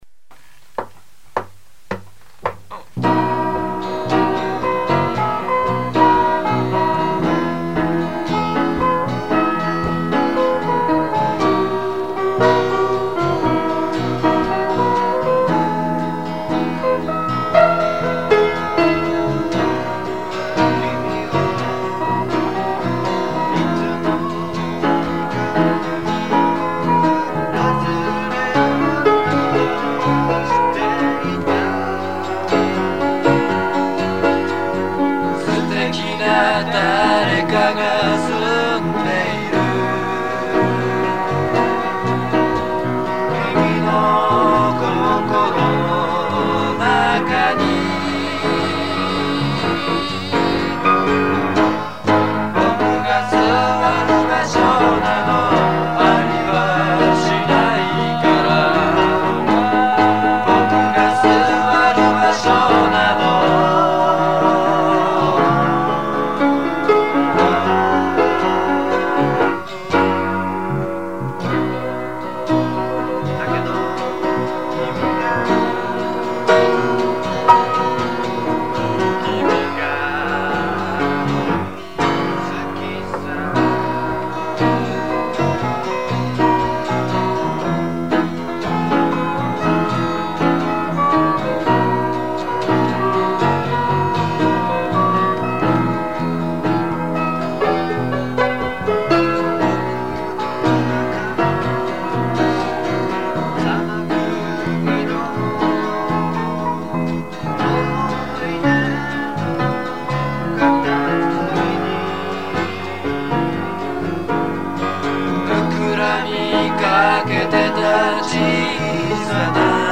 決まったスコアーが無いので、気分で皆好きな用に音を出してました。
友達の家で録音しました。　ただのステレオカセットでの録音です。
これも自作のミキサーとエフェクターを使いました。